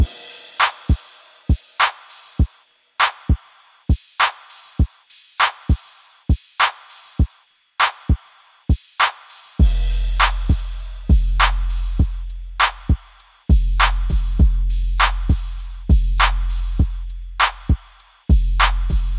嘻哈大鼓与808
描述：漂亮的开放性帽子，流畅的鼓声。
Tag: 100 bpm Hip Hop Loops Drum Loops 3.23 MB wav Key : Unknown